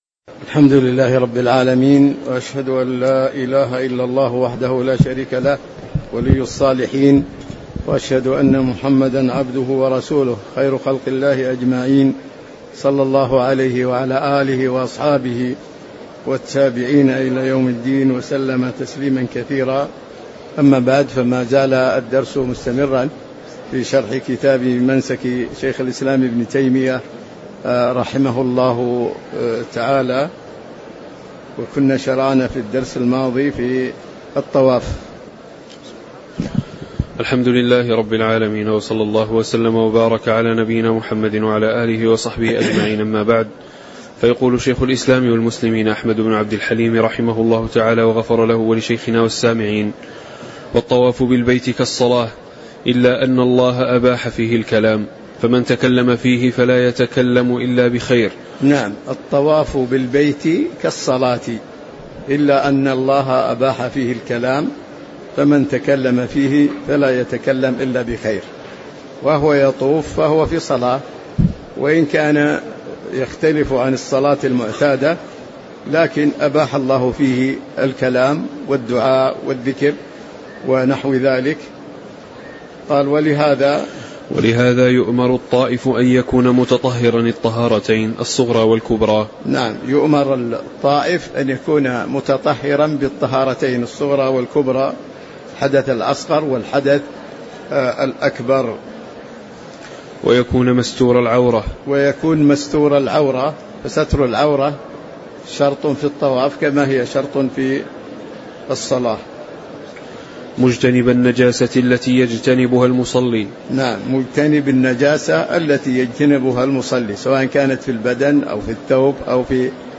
تاريخ النشر ١ ذو الحجة ١٤٤٦ هـ المكان: المسجد النبوي الشيخ